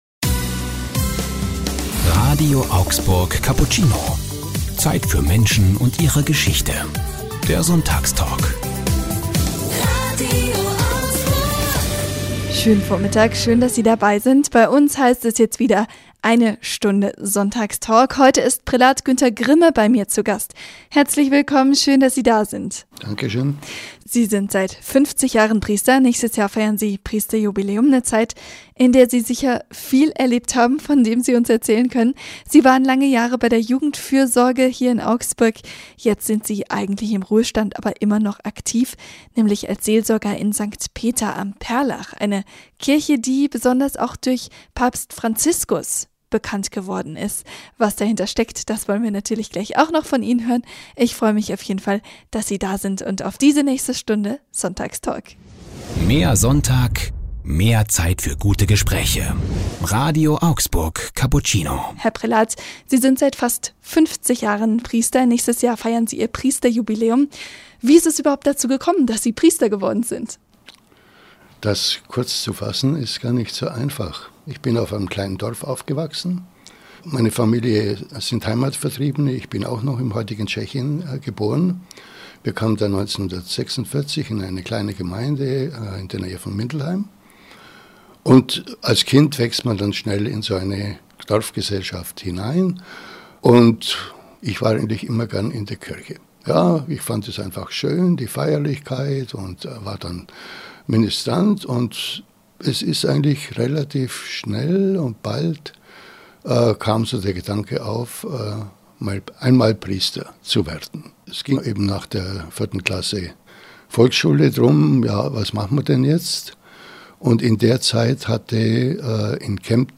Sonntagstalk